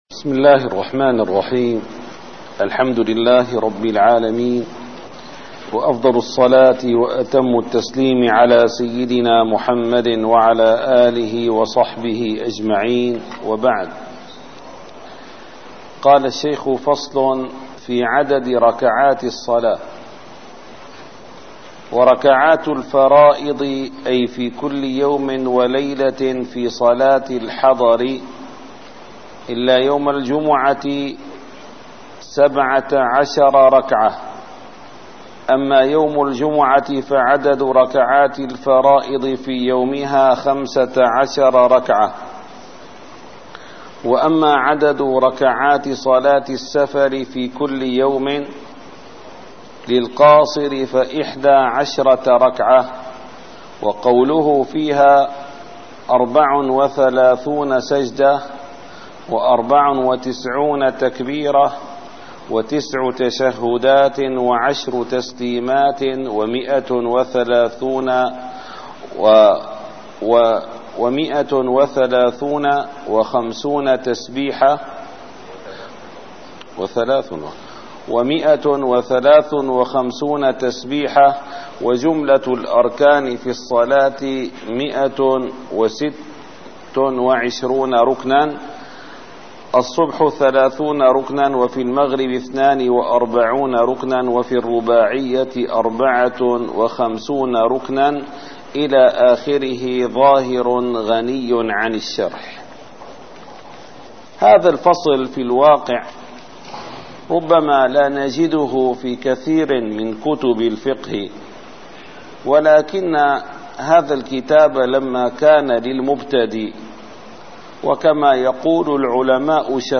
- الدروس العلمية - الفقه الشافعي - شرح ابن قاسم الغزي - الدرس السادس والأربعون: فصل في عدد ركعات الصلاة ص74